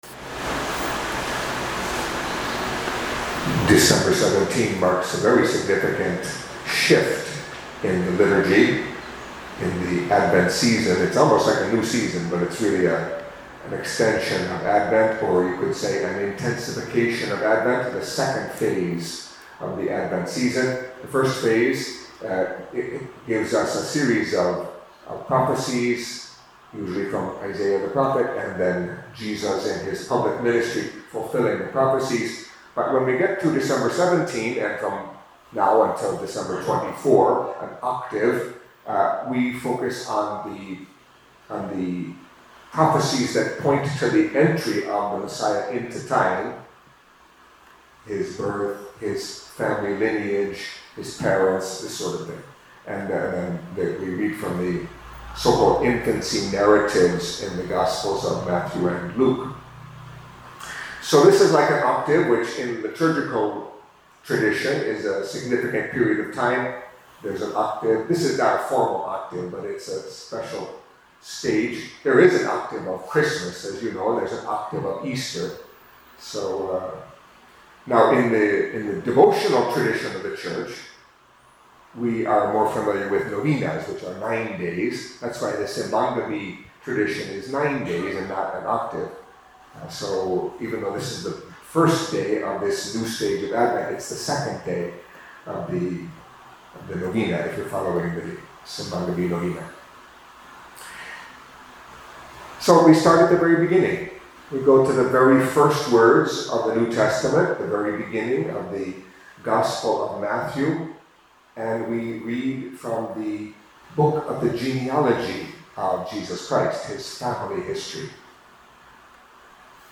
Catholic Mass homily for Tuesday of the Third Week of Advent